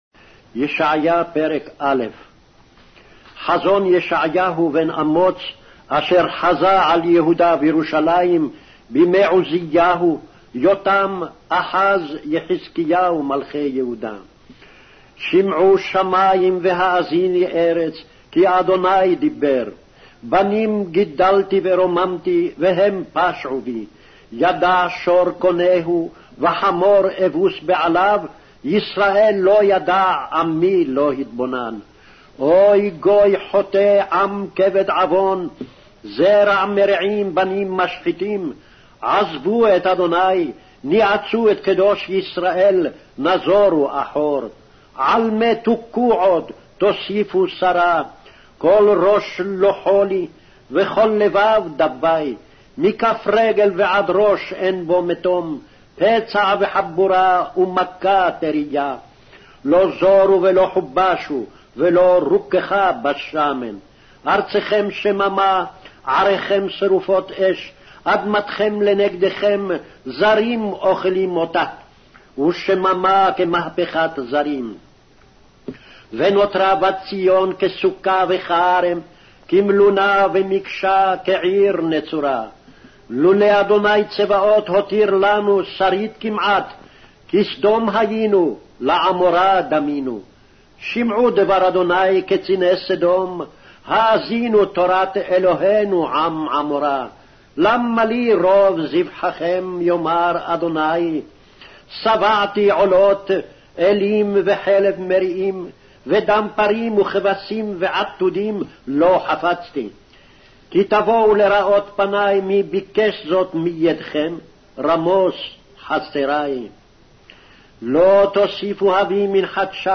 Hebrew Audio Bible - Isaiah 31 in Erven bible version